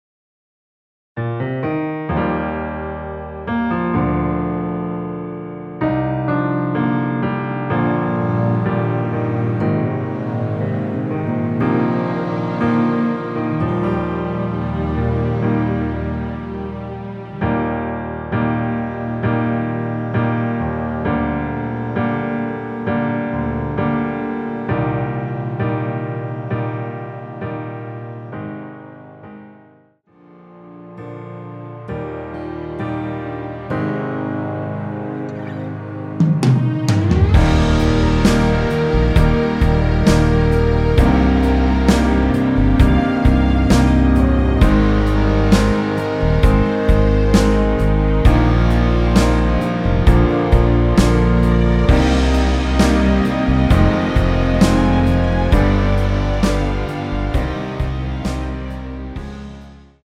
원키에서(-6)내린 MR입니다.
Eb
앞부분30초, 뒷부분30초씩 편집해서 올려 드리고 있습니다.
중간에 음이 끈어지고 다시 나오는 이유는